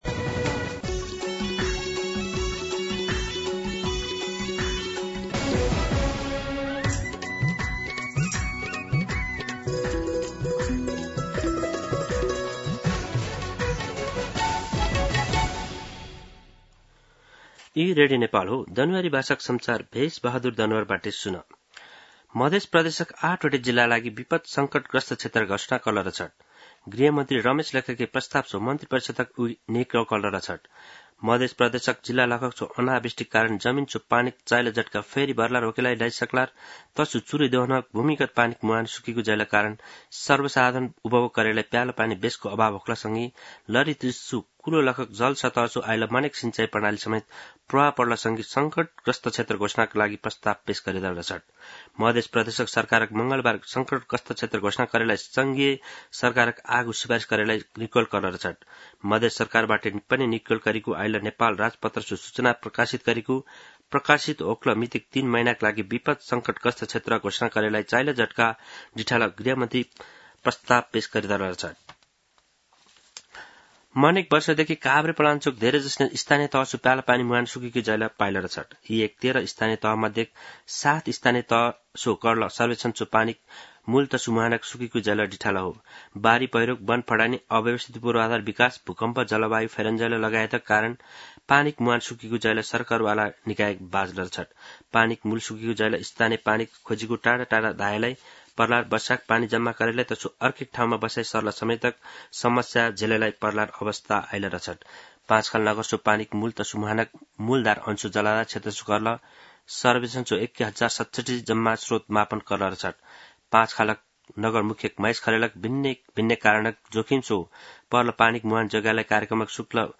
दनुवार भाषामा समाचार : ७ साउन , २०८२
Danuwar-News-04-7.mp3